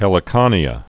(hĕlĭ-kŏnē-ə)